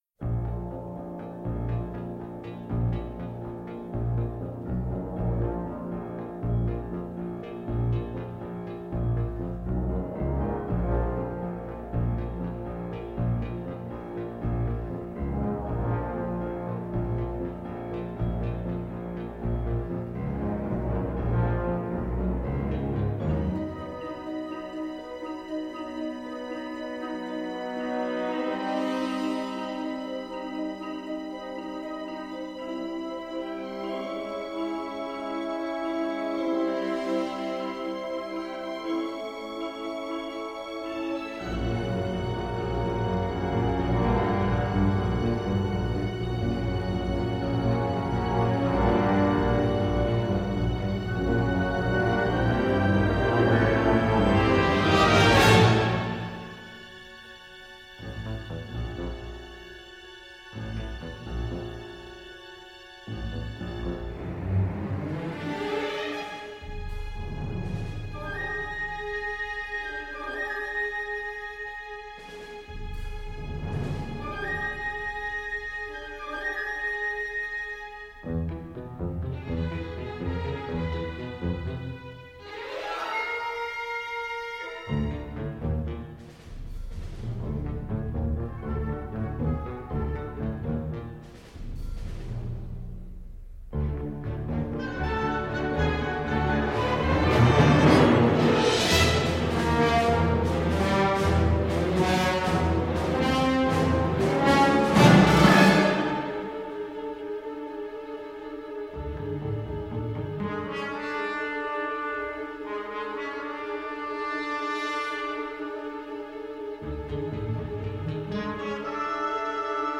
Le rendu sonore semble ici plus dynamique.